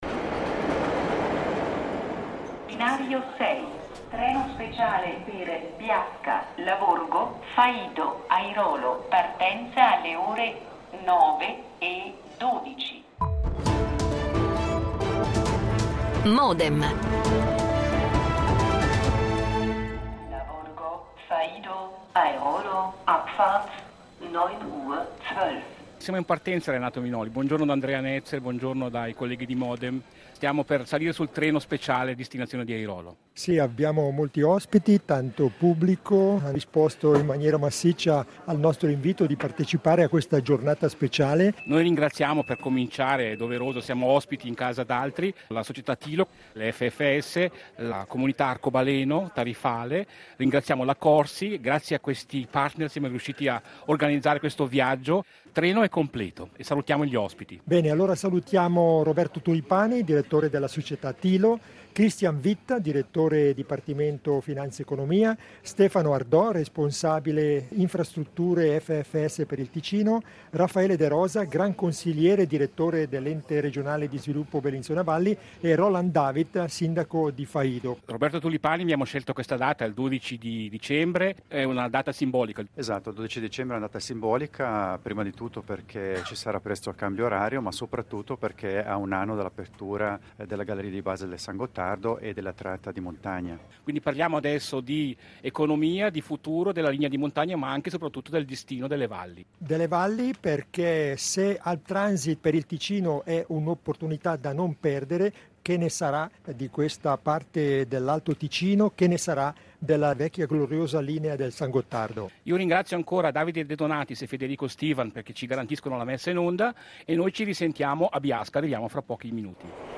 L'incontro-dibattito è stato registrato in viaggio, su un treno speciale da Bellinzona ad Airolo, sabato 12.12.2015, alla presenza del pubblico.